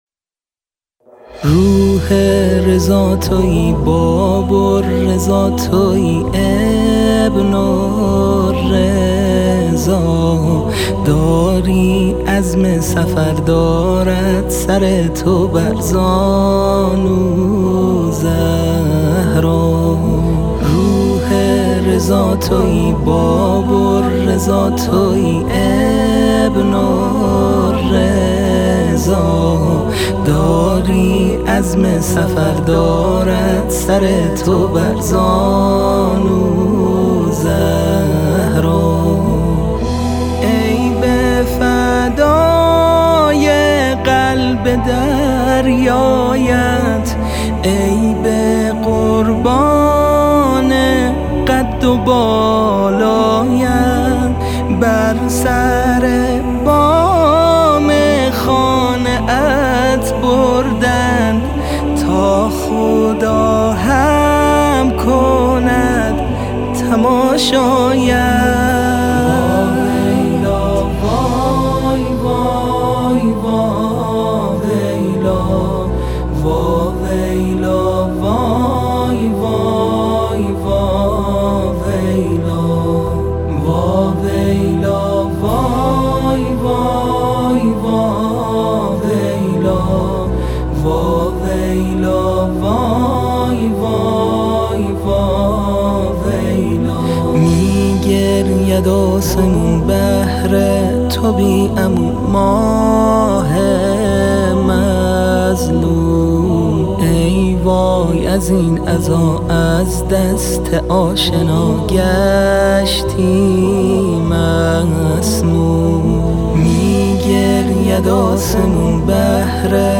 روضه و مرثیه ها
مداحی